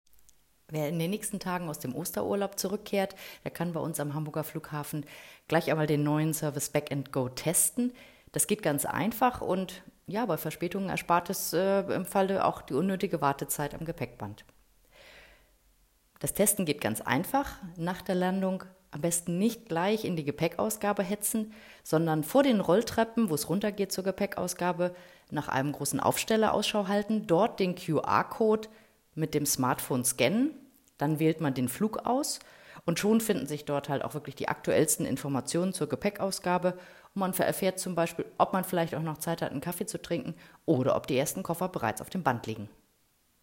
Audio-Statement